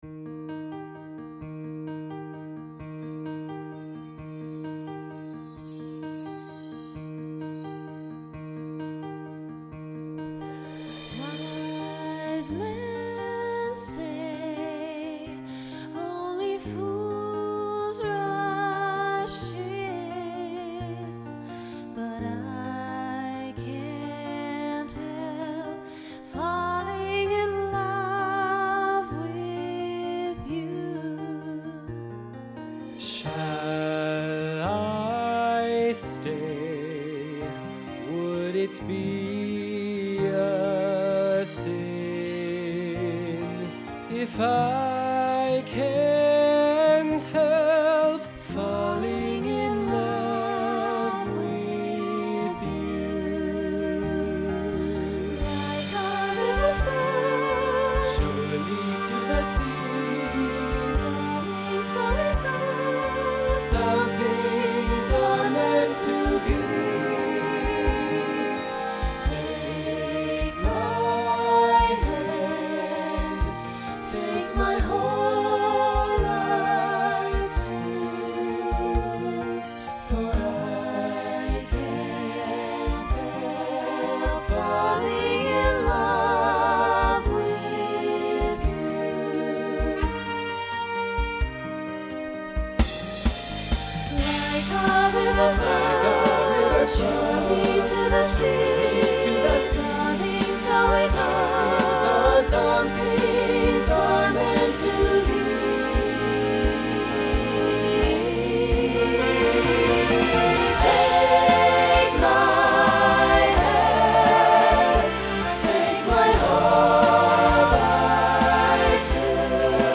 Voicing SATB Instrumental combo
Rock Decade 1960s Show Function Ballad